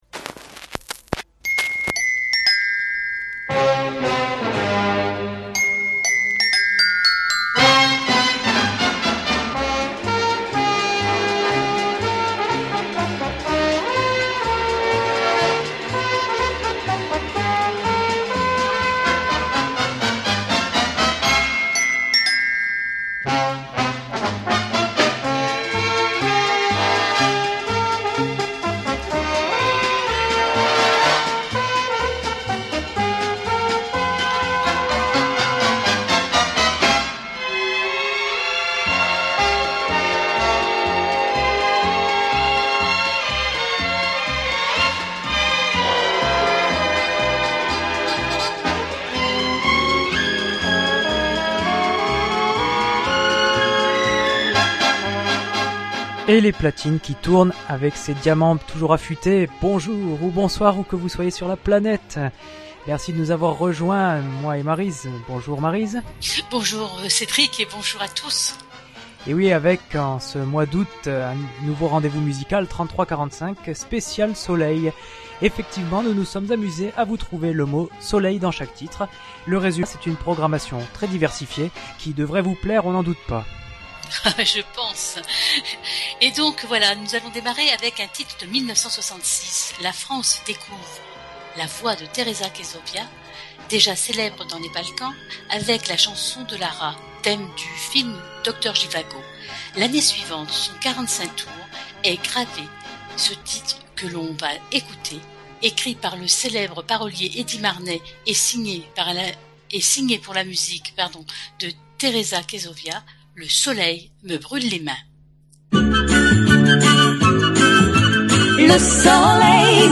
Le Podcast Journal, en partenariat avec Radio Fil, vous propose cette émission musicale dédiée aux années vinyles